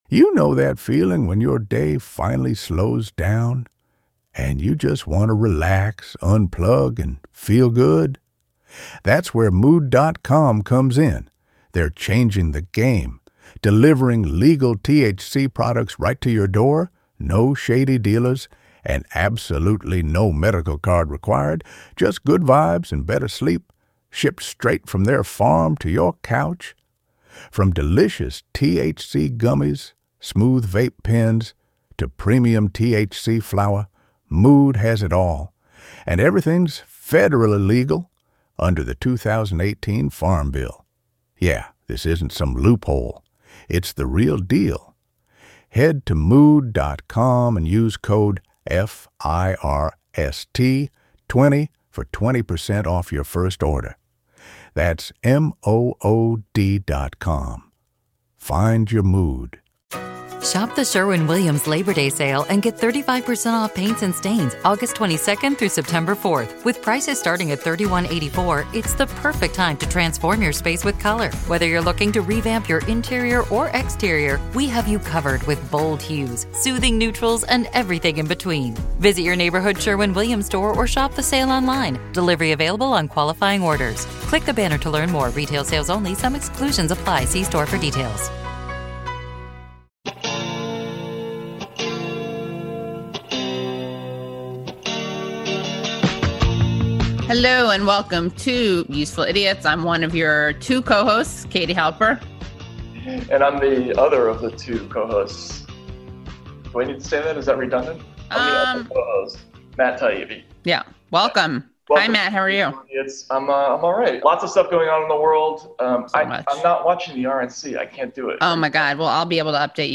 Attorney, author and former New York gubernatorial candidate Zephyr Teachout joins hosts Katie Halper and Matt Taibbi to talk about the role…
Play Rate Listened List Bookmark Get this podcast via API From The Podcast 3 Useful Idiots is an informative and irreverent politics podcast with journalist Aaron Maté and podcaster/writer Katie Halper. Episodes feature analysis of the political news of the week and exclusive interviews, with humor, commentary and dissection of why both Republicans and Democrats suck.